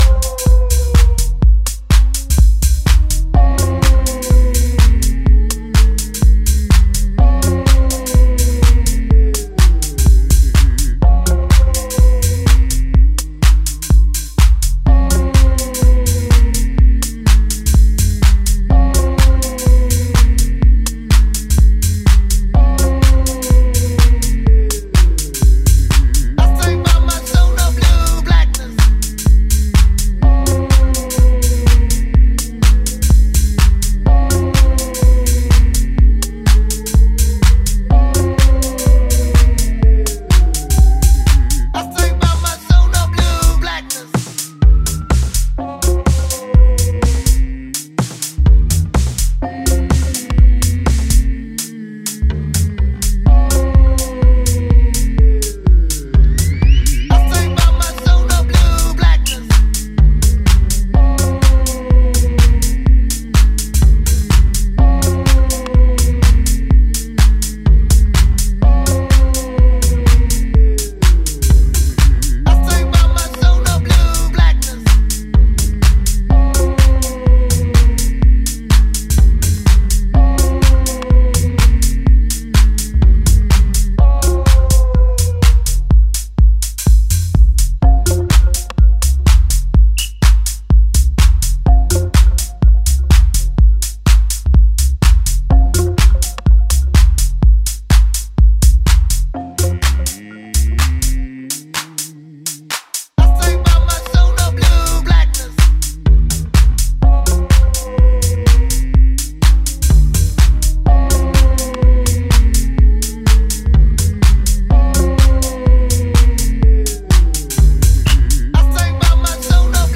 detail and timeless club music.